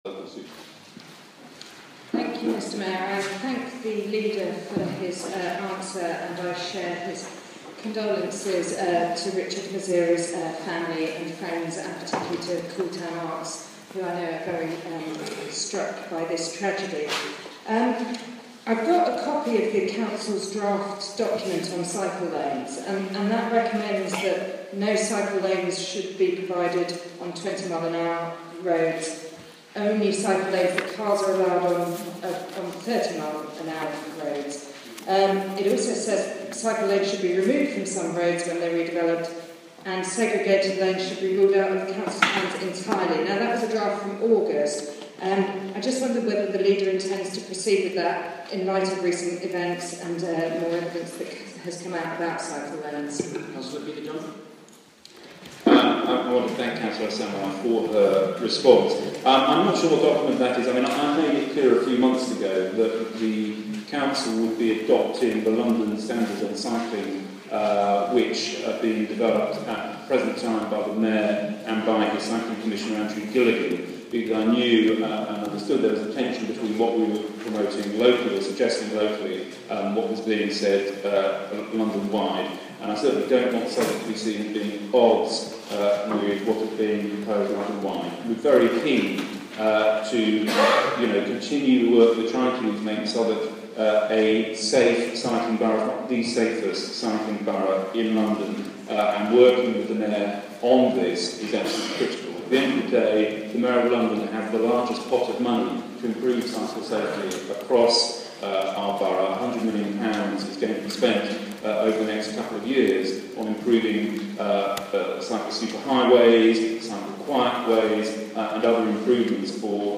Opposition leader questions council leader at Council Assembly 27 November 2013